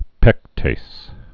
(pĕktās, -tāz)